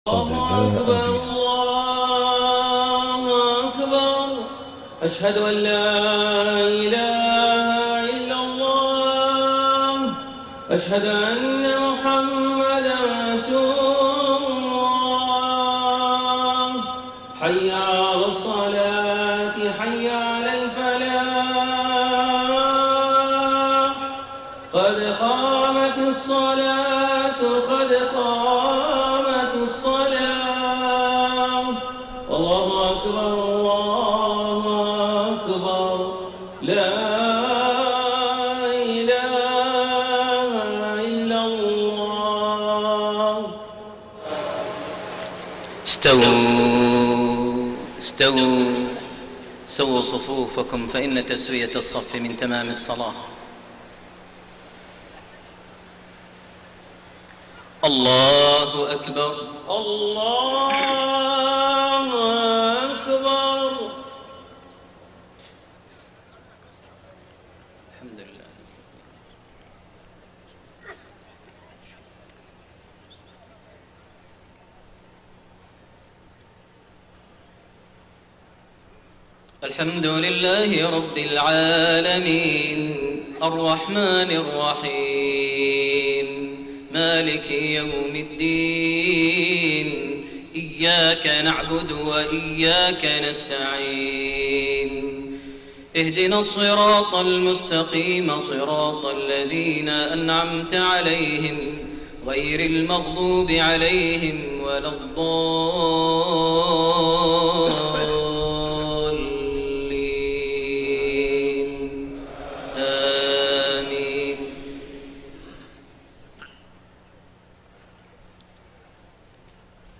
صلاة العشاء 7-5-1431 من سورة آل عمران 23-30 > 1431 هـ > الفروض - تلاوات ماهر المعيقلي